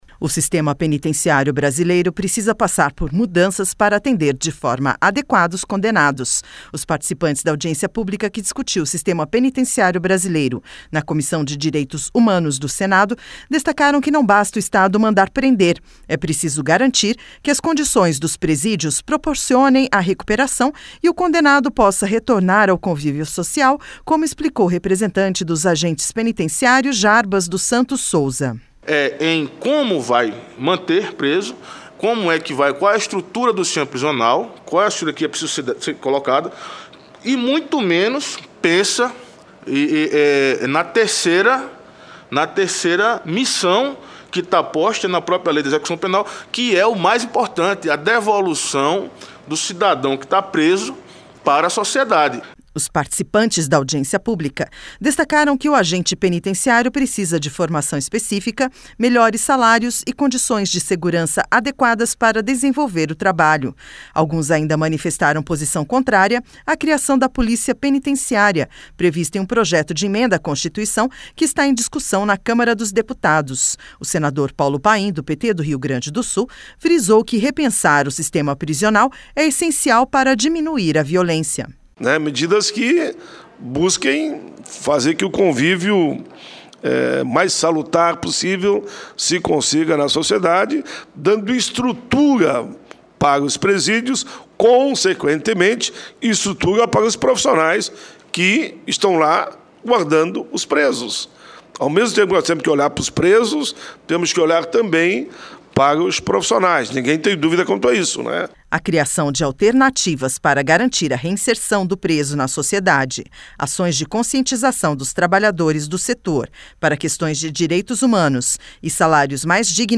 O senador Paulo Paim, do PT do Rio Grande do Sul, frisou que repensar o sistema prisional é essencial para diminuir a violência.